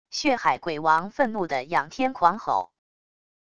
血海鬼王愤怒的仰天狂吼wav音频